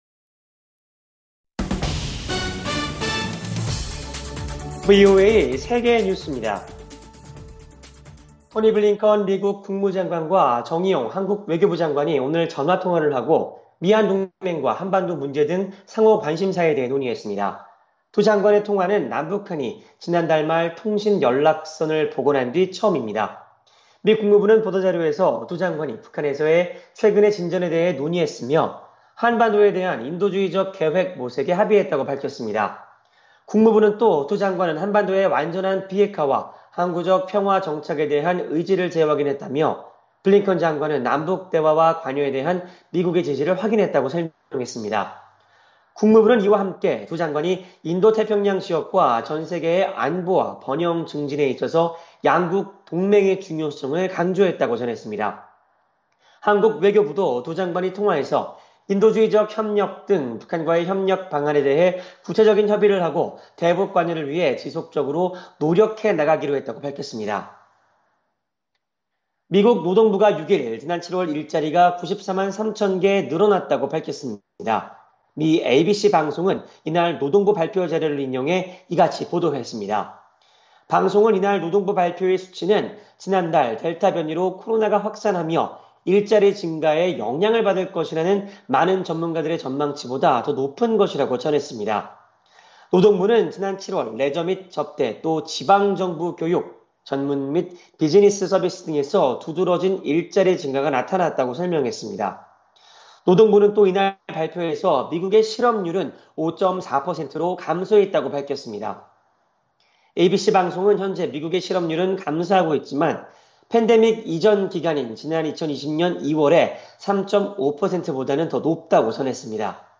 VOA 한국어 간판 뉴스 프로그램 '뉴스 투데이', 2021년 8월 6일 3부 방송입니다. 미 의회조사국은 조 바이든 행정부가 북한의 점진적 비핵화에 상응해 부분적 제재 완화를 추진할 것으로 전망했습니다. 미국은 한국전쟁 참전 미군 유해 발굴 문제를 비롯해 북한과 어떤 사안이든 논의할 준비가 돼 있다고, 미 국무부 부차관보가 거듭 강조했습니다. 한국 정부가 남북 통신선 복원을 계기로 평화 프로세스를 재가동하려는 의지를 보이지만, 워싱턴은 임기 말 문재인 정부의 남북관계 개선 시도에 큰 의미를 두지 않는 분위기입니다.